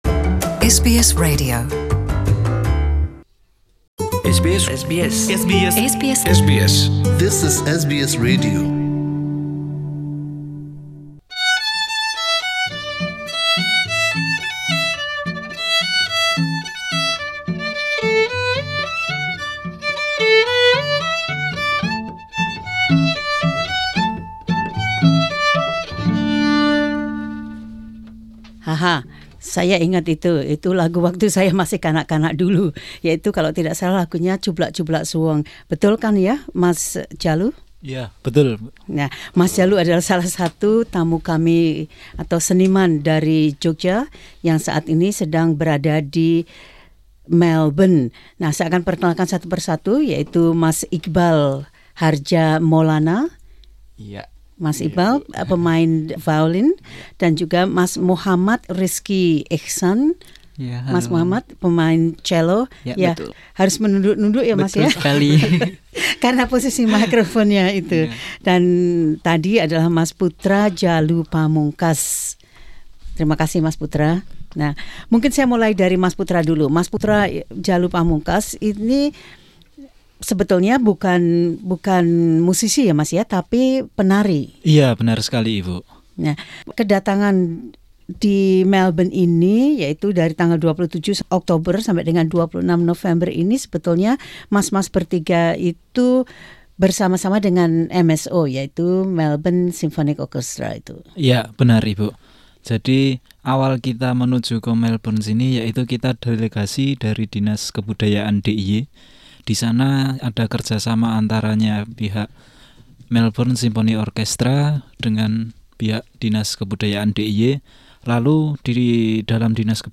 berbicara tentang waktu dan magangnya bersama Melbourne Symphony Orchestra (MSO) di mana mereka juga memainkan contoh musiknya di studio.
playing a song in the studio SBS in Melbourne